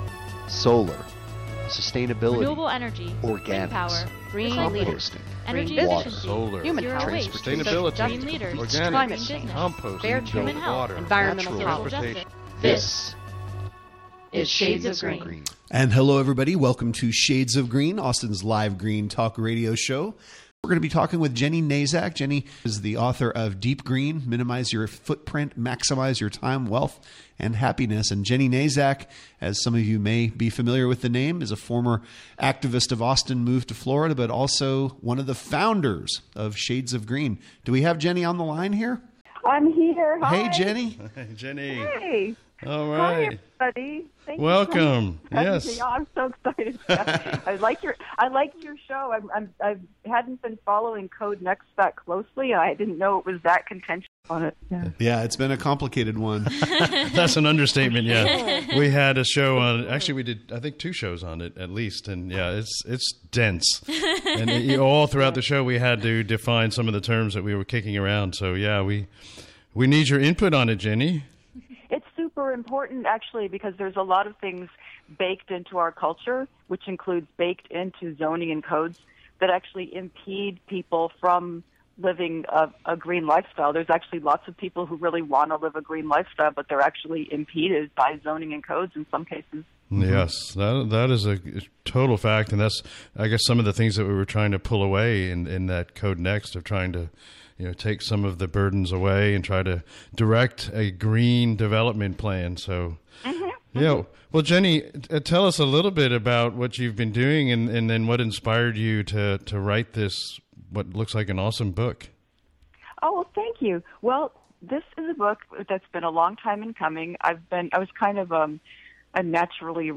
Interview on “Shades of Green” Radio Show